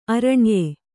♪ arṇye